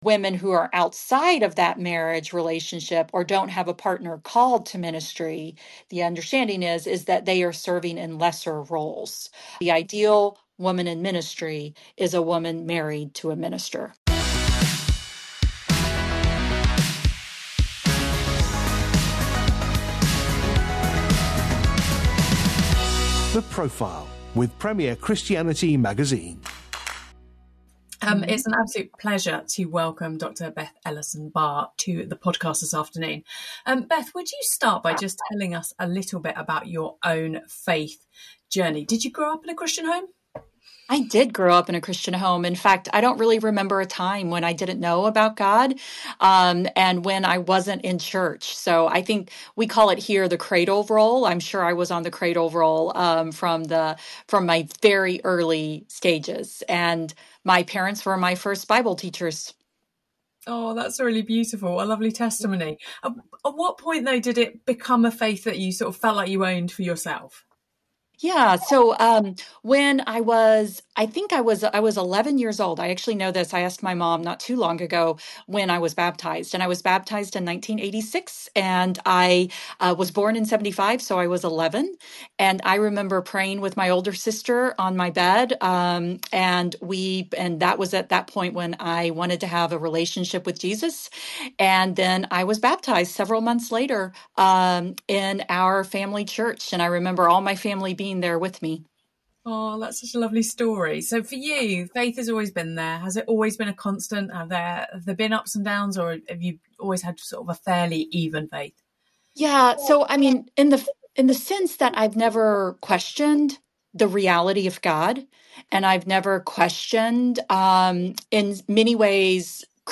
for a conversation